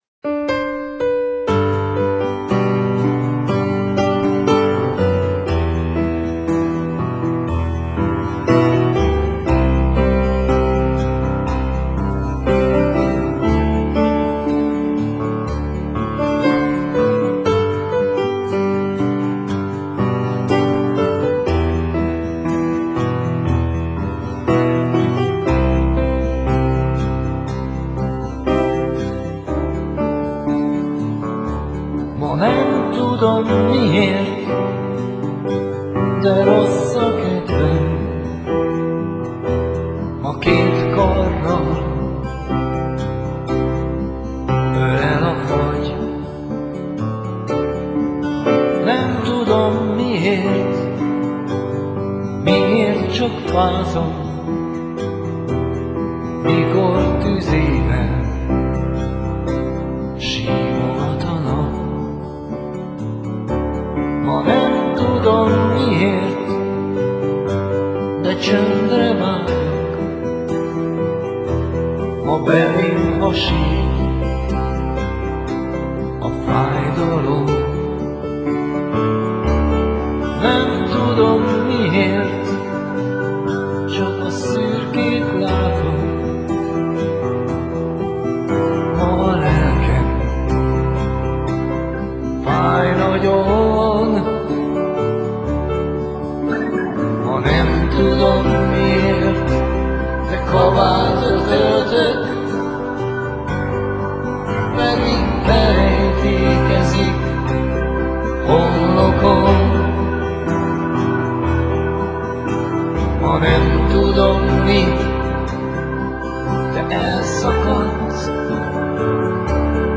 Megzenésített vers